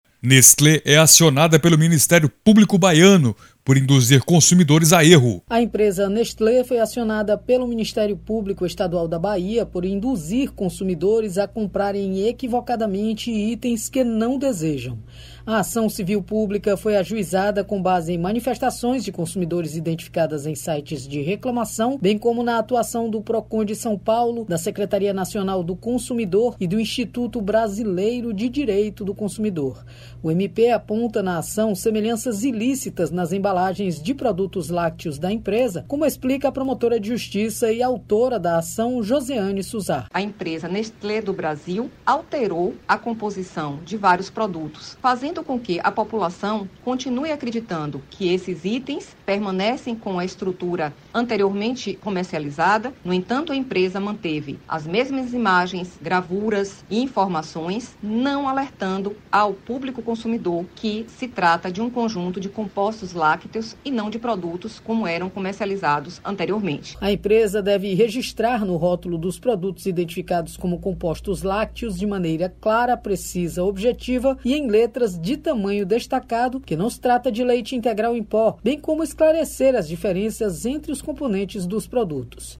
Áudio NotíciasBrasil